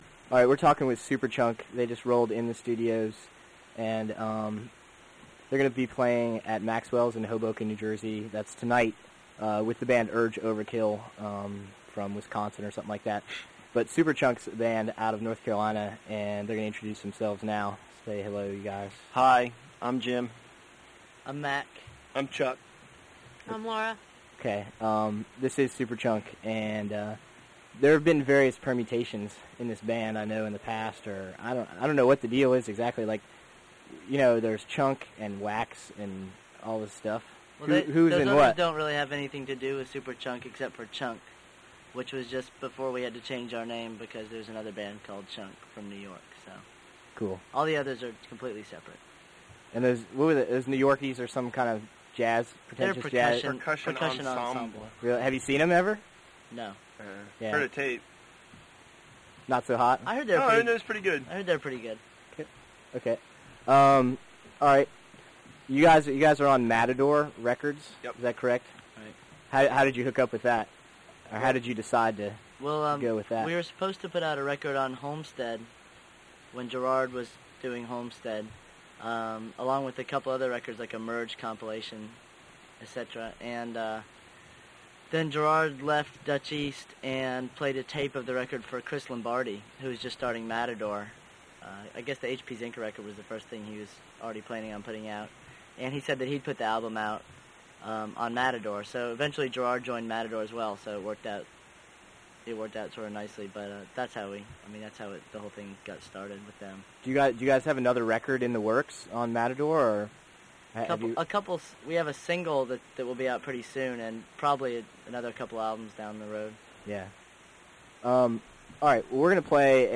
Superchunk_interview_scoped.mp3